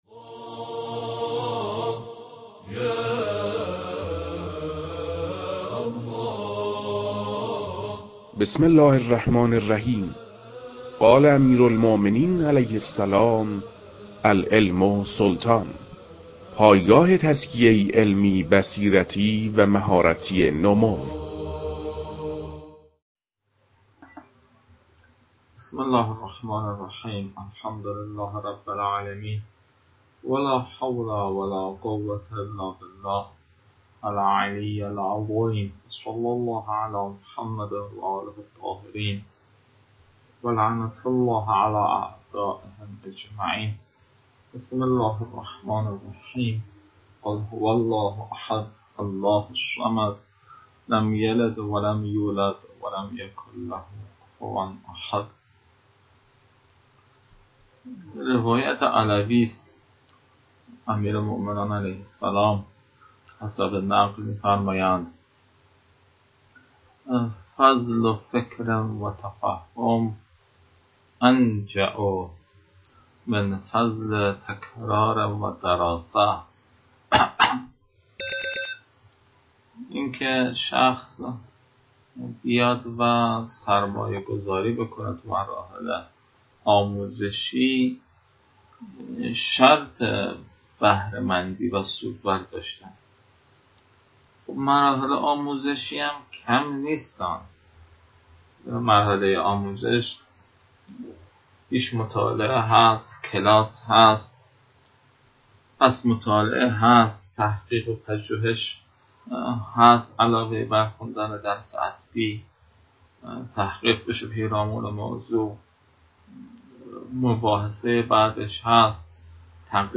فایل های مربوط به تدریس مباحث تنبیهات معاطات